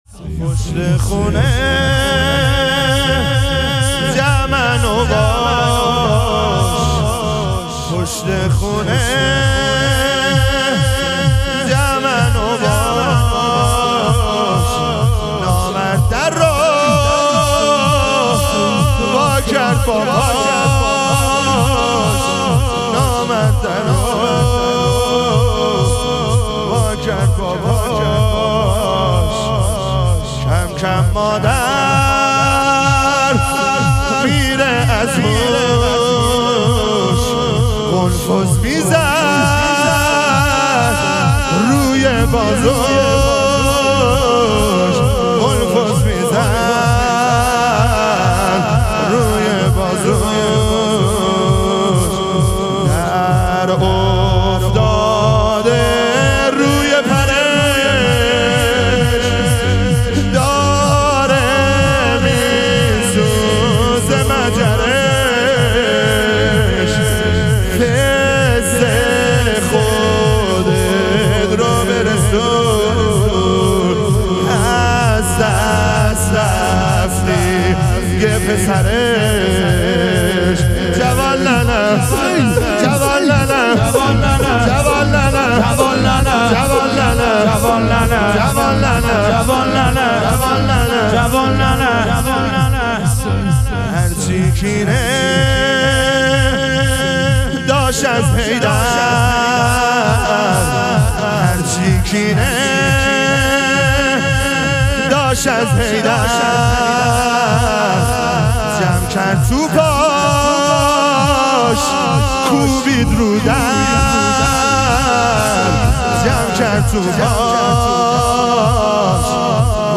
شام غریبان حضرت زهرا علیها سلام - لطمه زنی